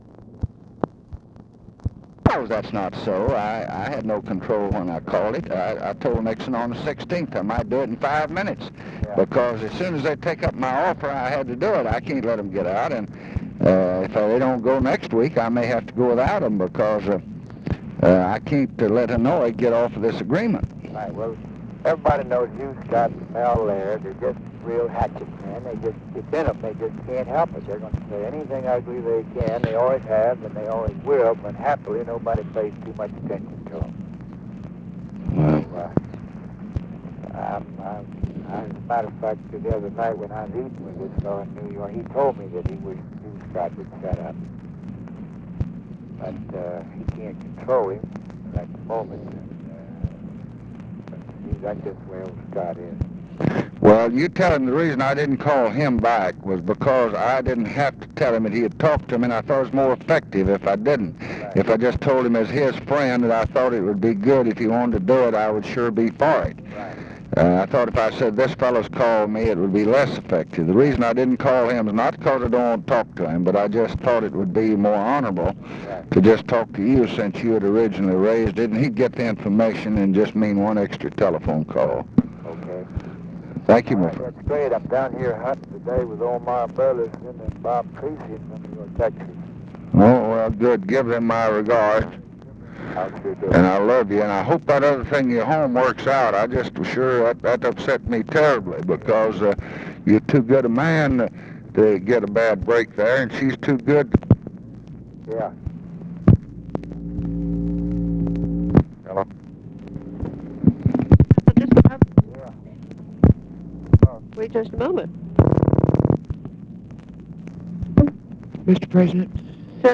Conversation with GEORGE SMATHERS, TELEPHONE OPERATOR and OFFICE SECRETARY, November 23, 1968
Secret White House Tapes